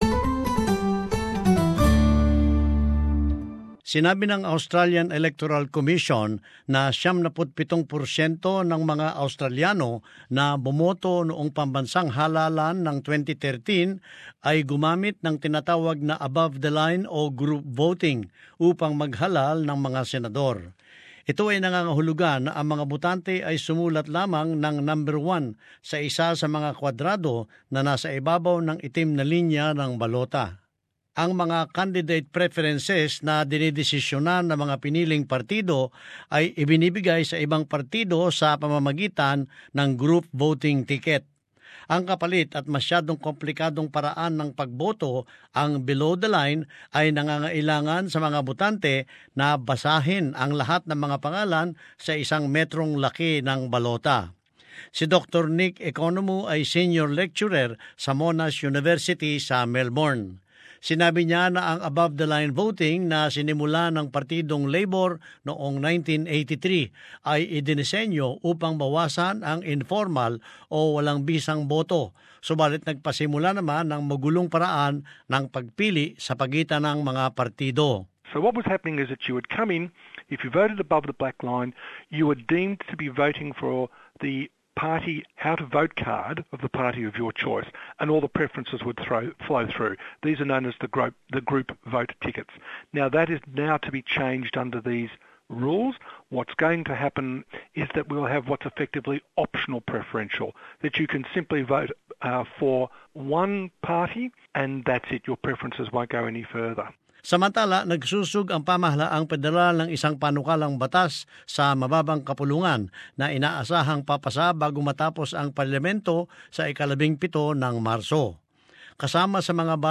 This report looks at the suspected implications ahead of an already highly-anticipated general election.